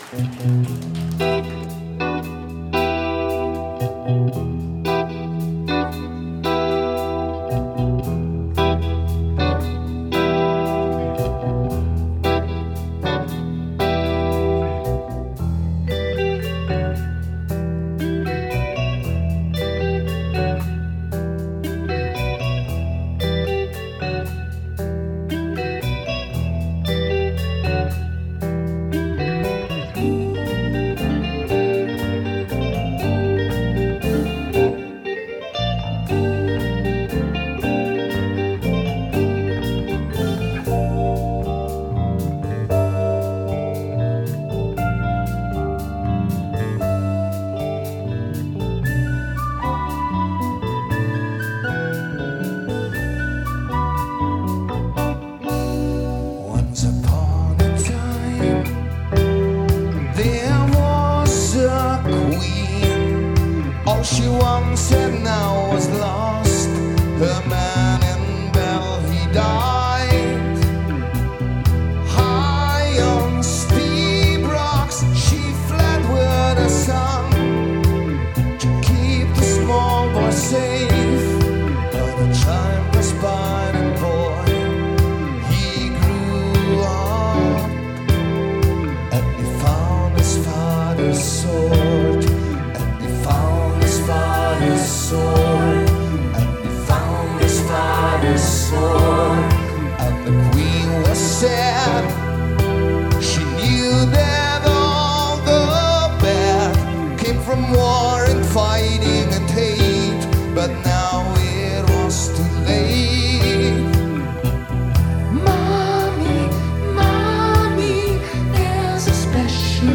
progressive pop-rock music band from south tyrol